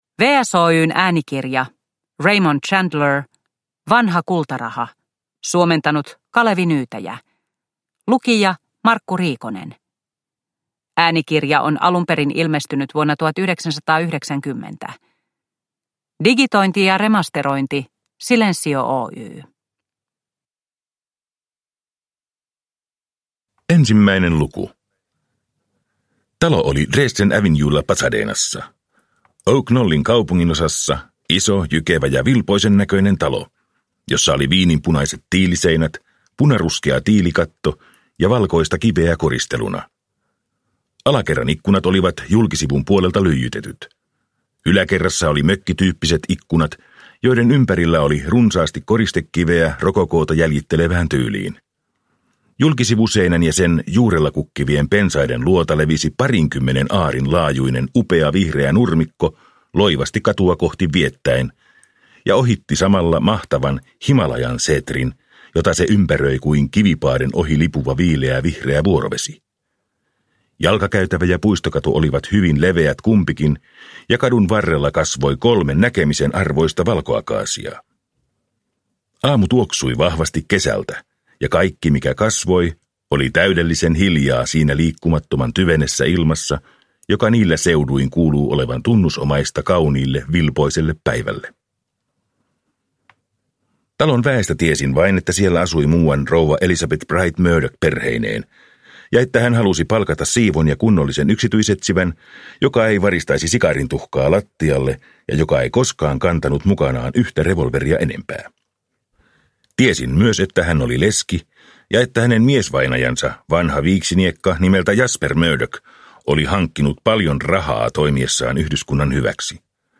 Äänikirja on ilmestynyt 1990.